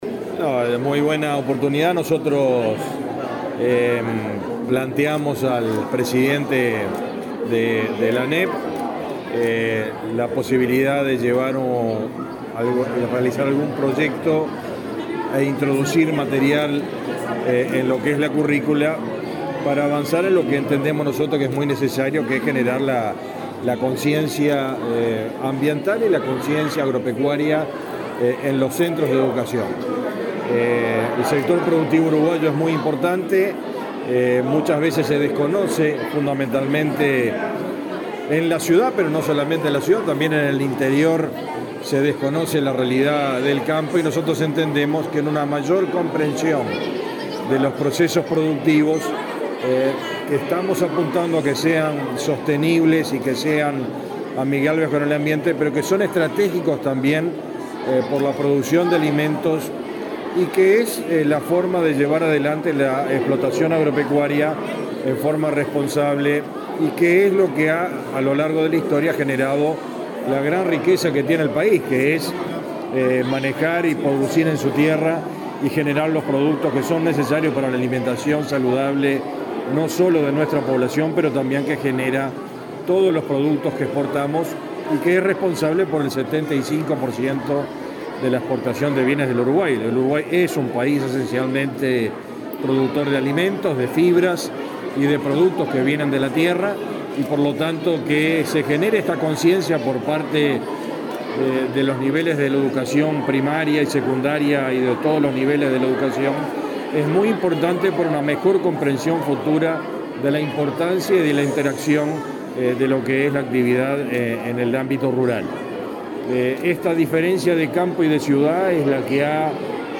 Declaraciones a la prensa del ministro Fernando Mattos
Luego, Mattos dialogó con la prensa.